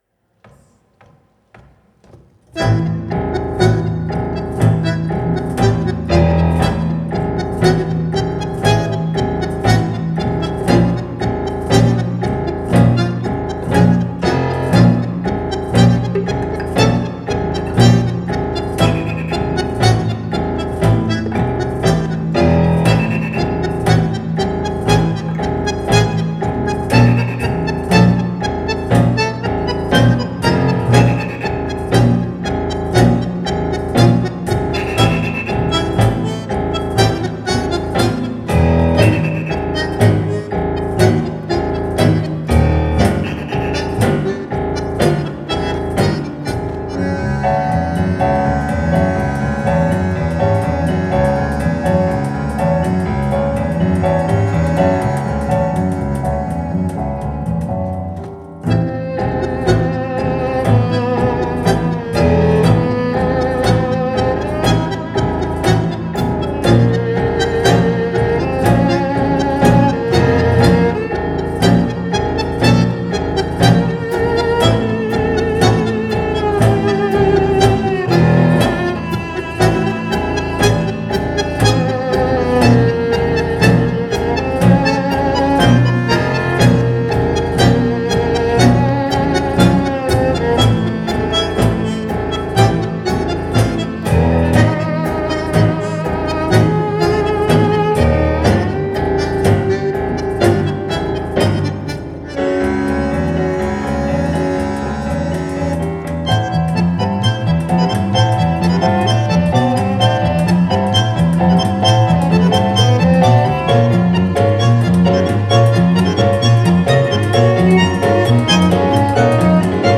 Танго
live